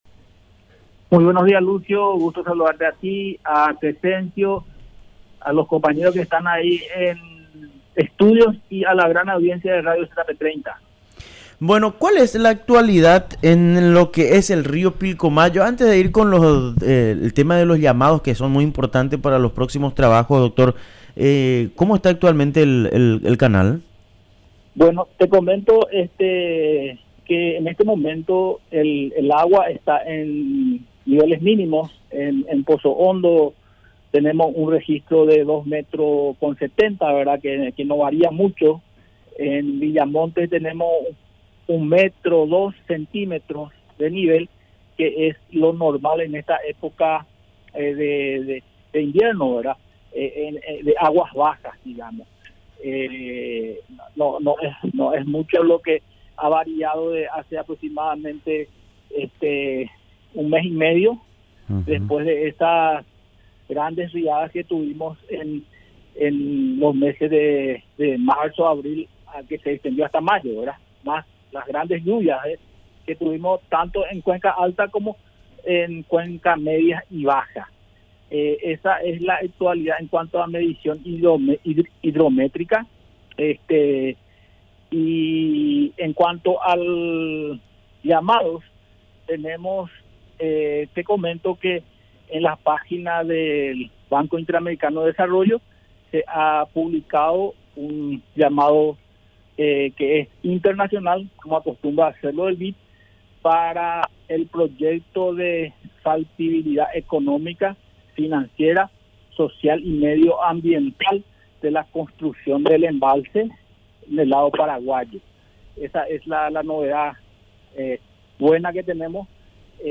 Entrevistas / Matinal 610 Proyecto de construcción de embalse Jul 16 2025 | 00:21:28 1x 00:00 / 00:21:28 Subscribe Share RSS Feed Share Link Embed